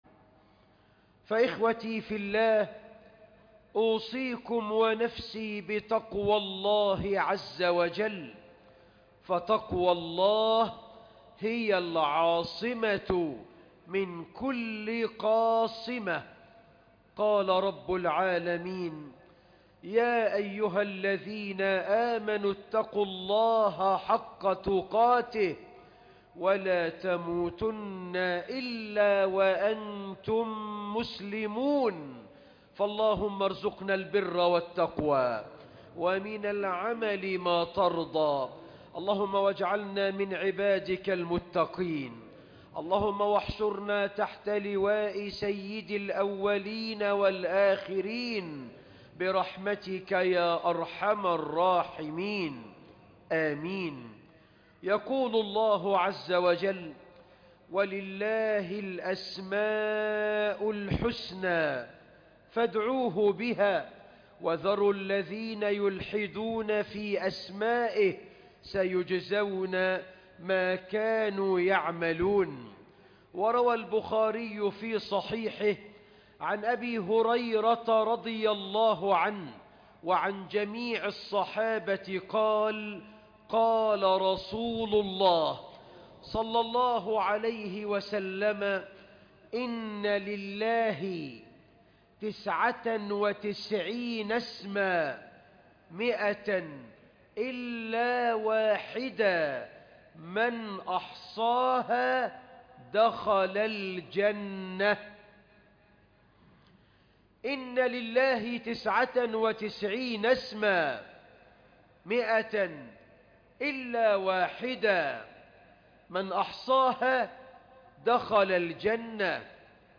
الخطبة كاملة شرح اسم الله الحكيم